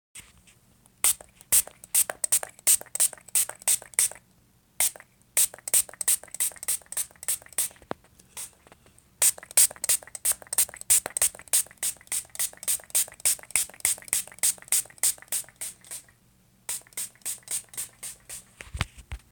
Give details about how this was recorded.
Field Recording – Spray Bottle